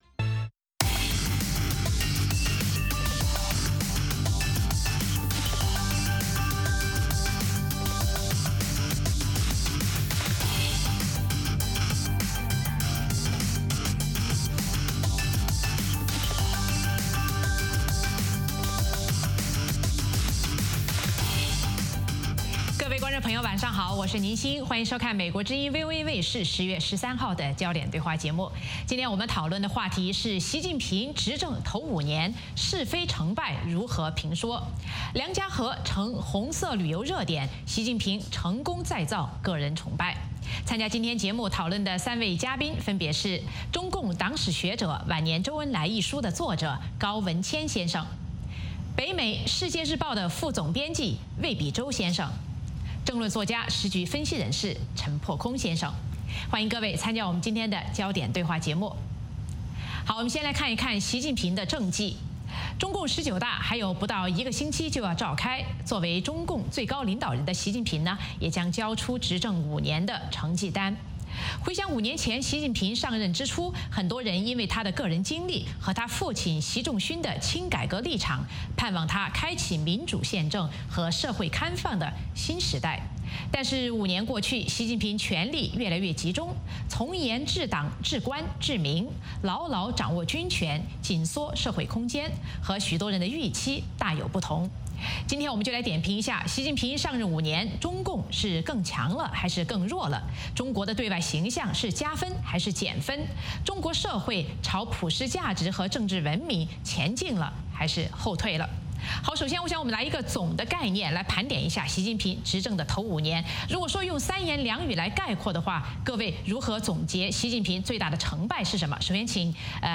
美国之音中文广播于北京时间早上6－7点重播“焦点对话”节目。《焦点对话》节目追踪国际大事、聚焦时事热点。邀请多位嘉宾对新闻事件进行分析、解读和评论。